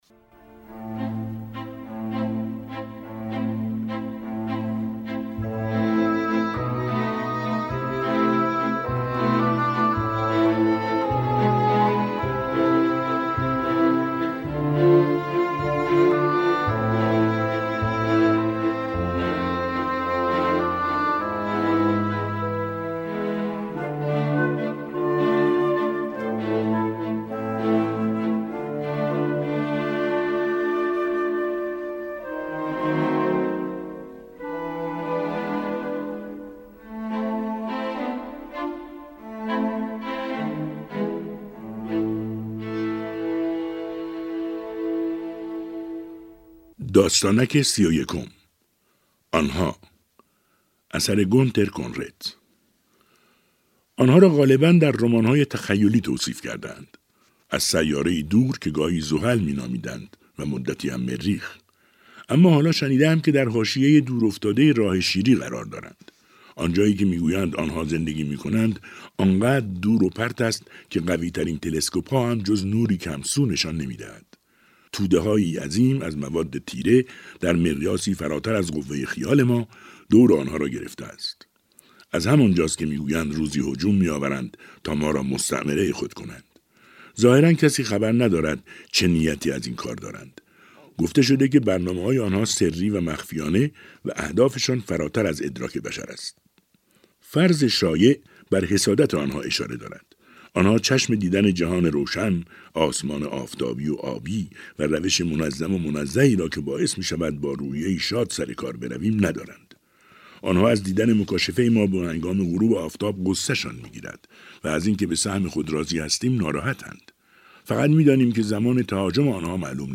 ۴۴ داستانک با صدای بهروز رضوی بازخوانی می‌شود + صوت
چهل و چهار داستانک از نویسندگان مشهور جهان در برنامه «کتاب شب» رادیو تهران با صدای بهروز رضوی، از روز شنبه (۲۸ تیر ماه) تا پنجشنبه (۲ مرداد ماه)، بازخوانی خواهد شد.